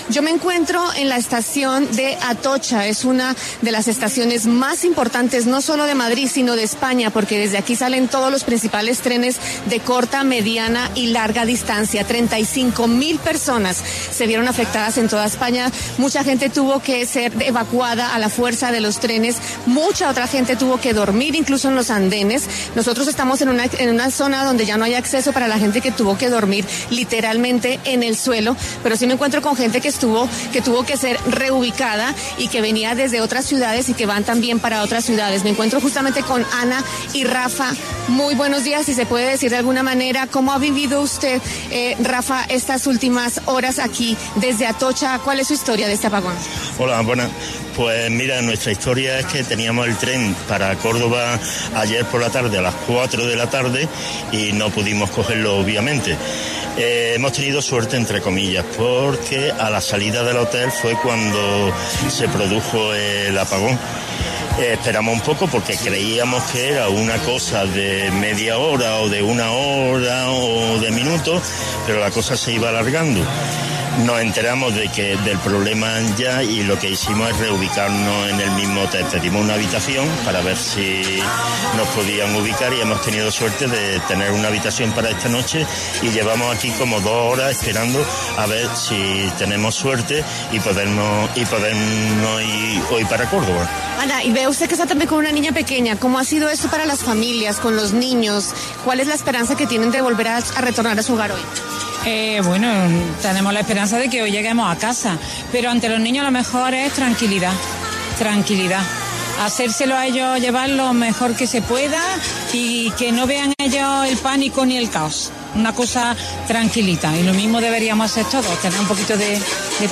Ante esta situación, La W radio pudo contar con testimonios de personas que se encuentran atascadas en la estación de Atocha.